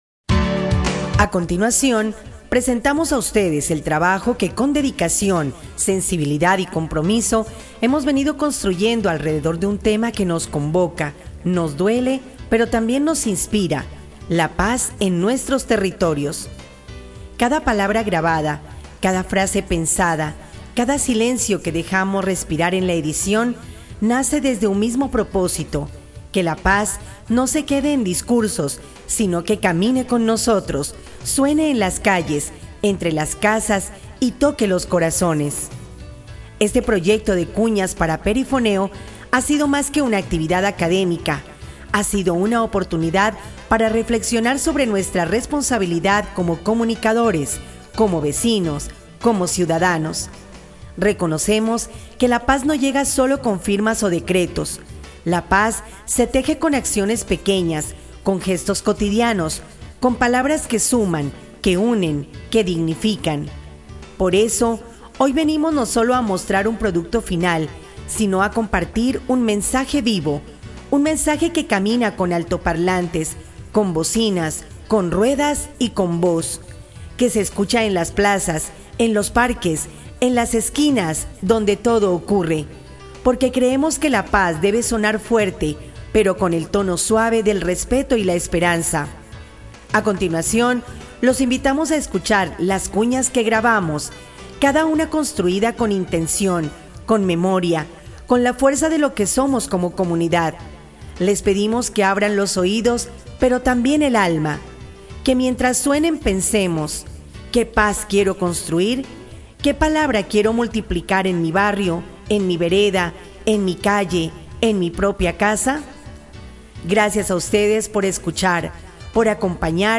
PERIFONEOS
Esta serie de audios recoge el perifoneo callejero como estrategia de comunicación territorial y comunitaria. Desde las calles, la voz amplificada informa, convoca y fortalece los vínculos sociales en el territorio.